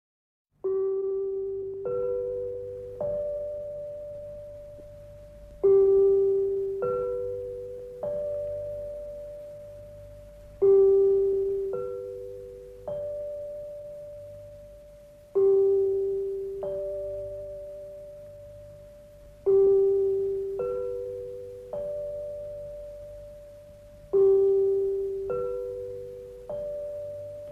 Sintonia de l'emissora